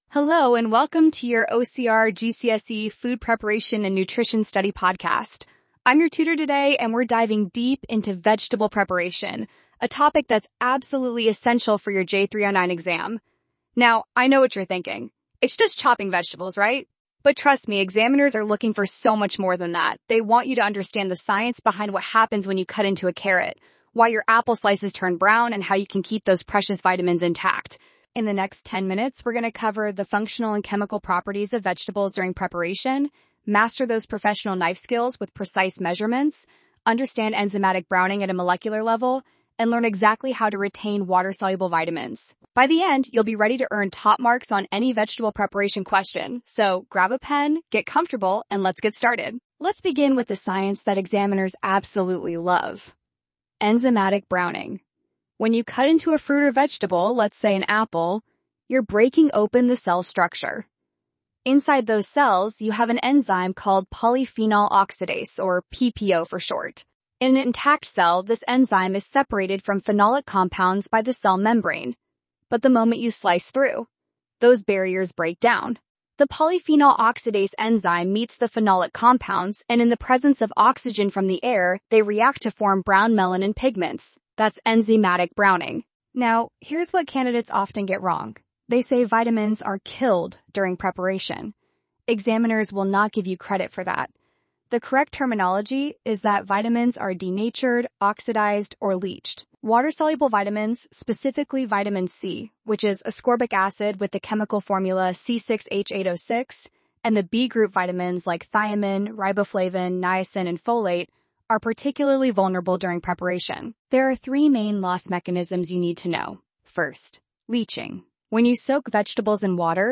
vegetable_preparation_podcast.mp3